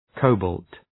Προφορά
{‘kəʋbɔ:lt}